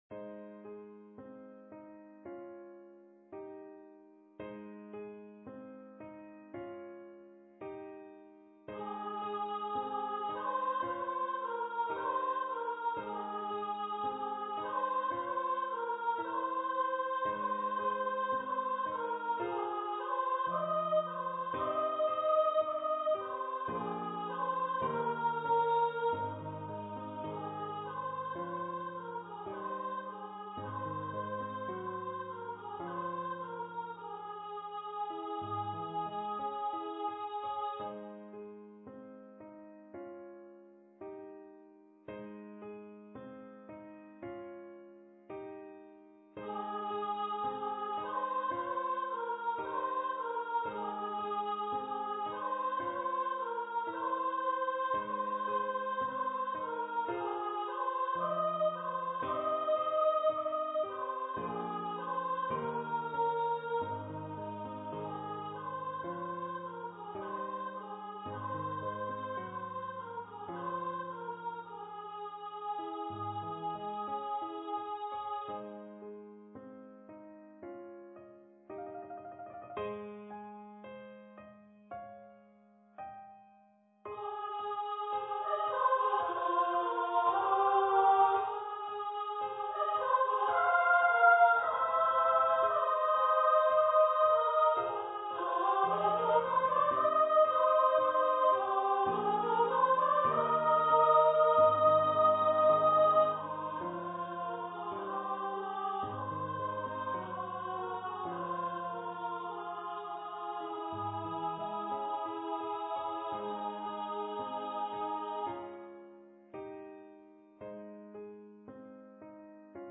for female voice choir
Choir - 4 part upper voices